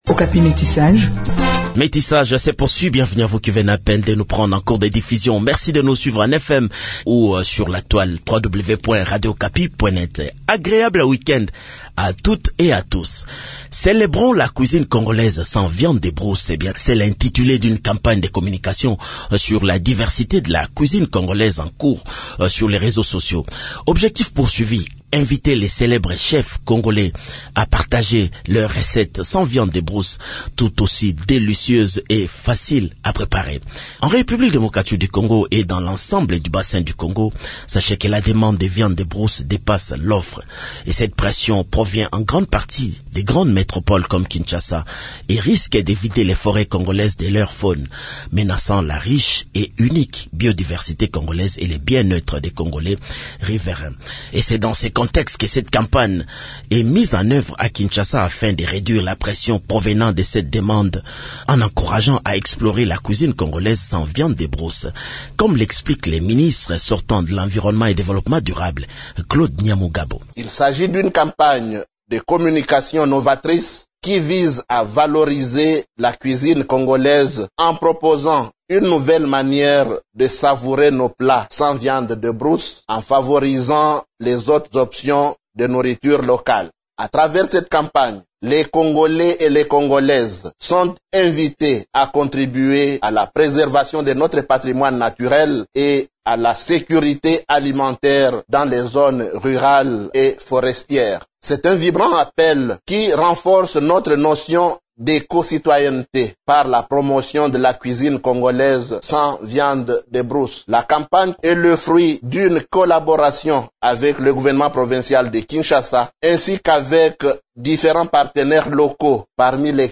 Ecoutez les explications du ministre sortant de l’Environnement et Développement durable, Claude Nyamugabo.